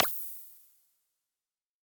menuclick.mp3